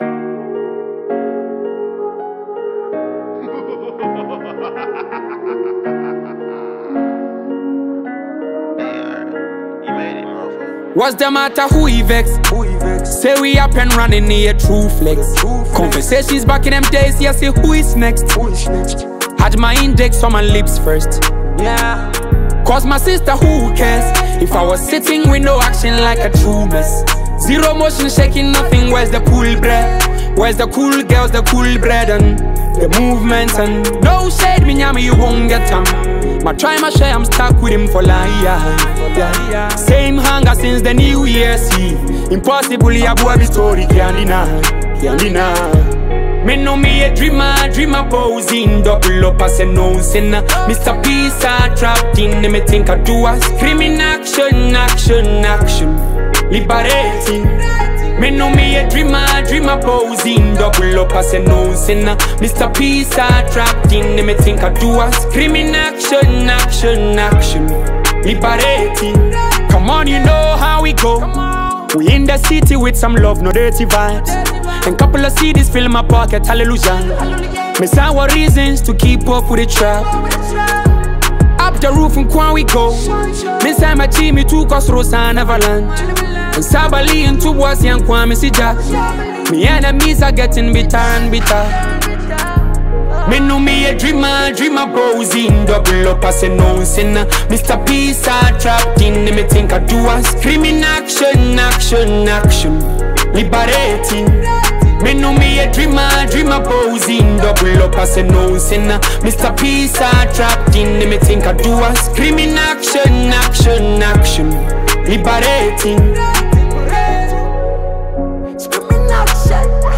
Talented rapper